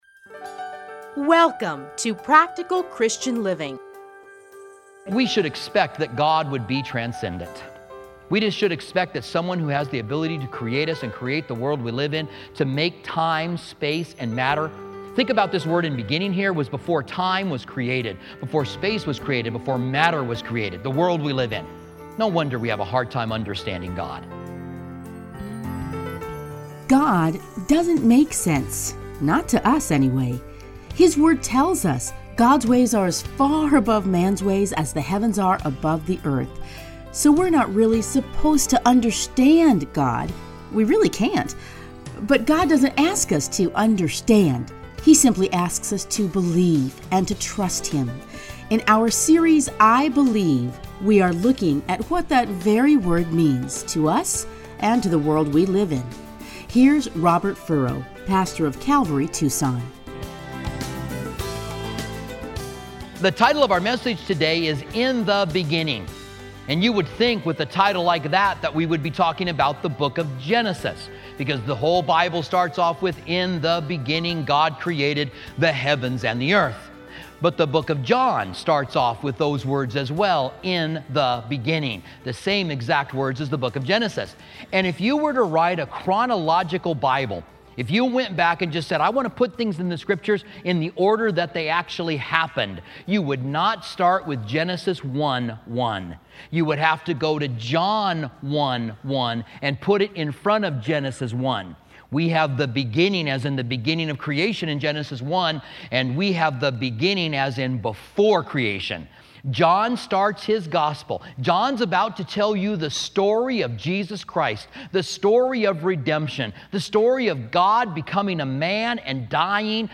edited into 30-minute radio programs